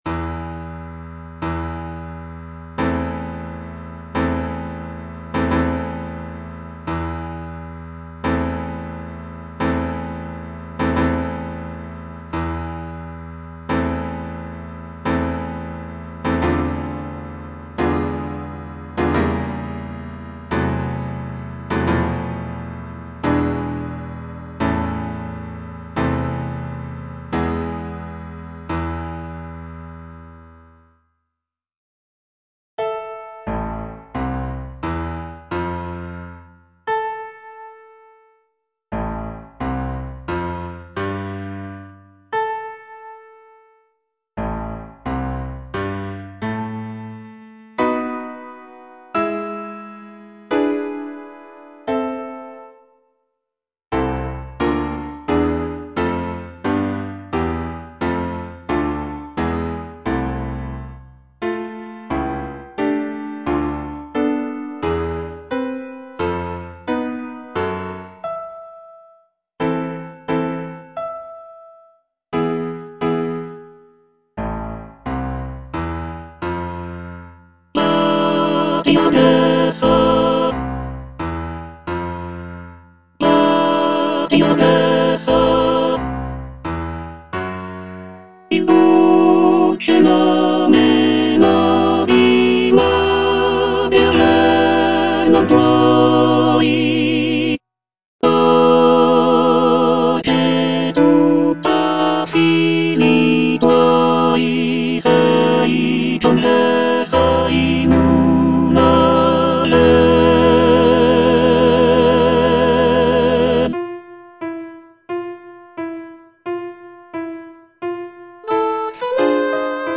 Tenor I (Emphasised voice and other voices)